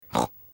• Качество: высокое
Свинка Пеппа весело хрюкает